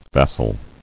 [vas·sal]